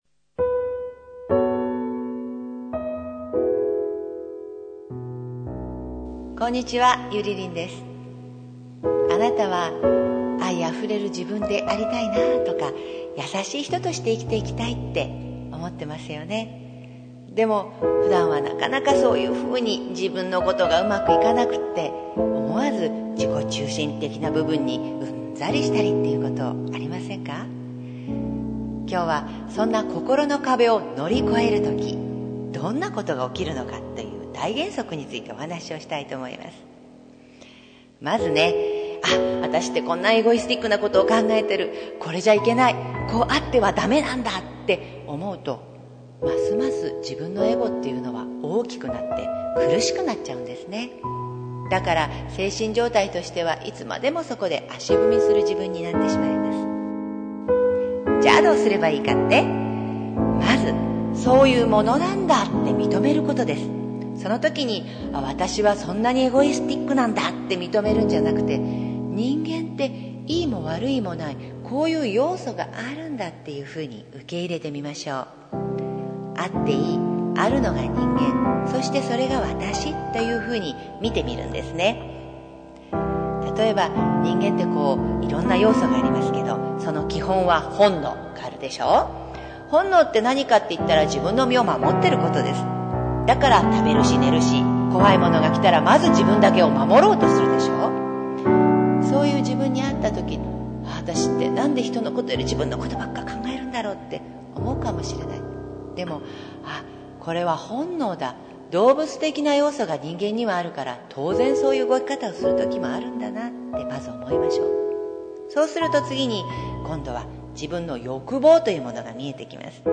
３分間フリートークです。